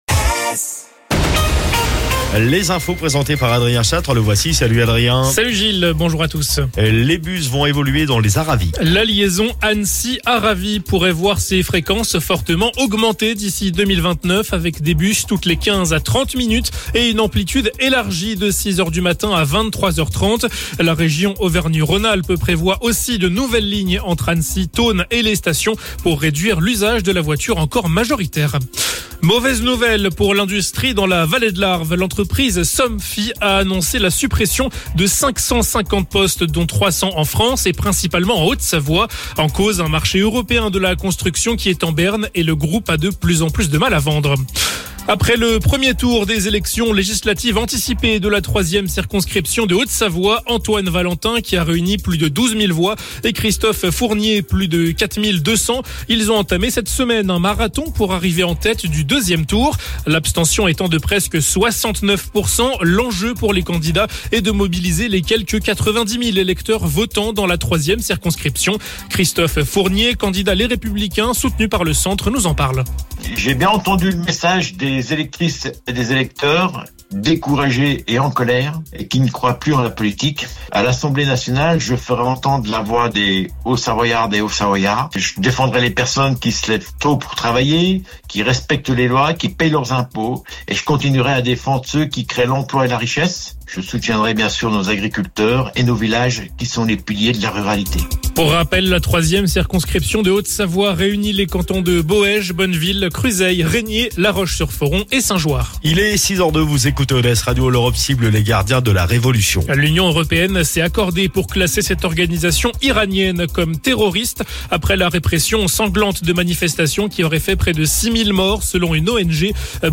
Le Flash Info, le journal d'ODS radio